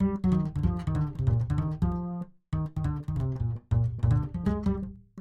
Что т такое наиграл, не уверен что везде попал.